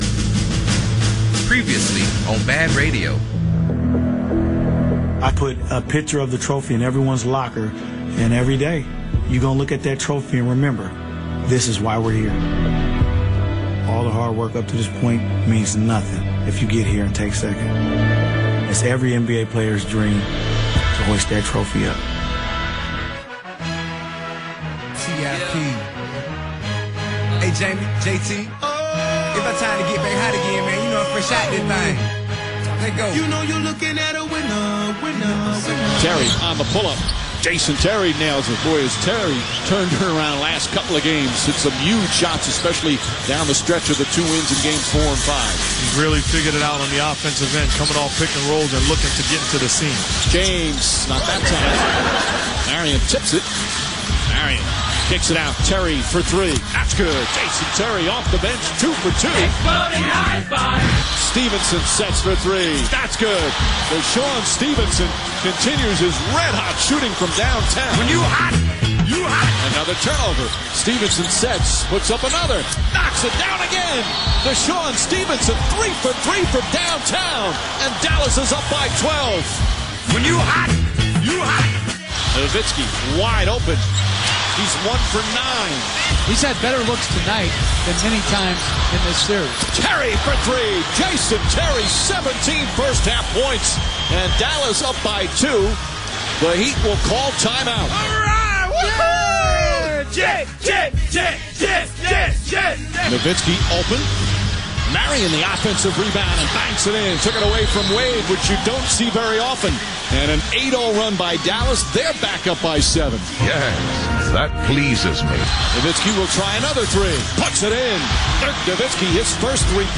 The BaD Radio show open is another keeper, so give it a listen and tell me if you get more sports happy.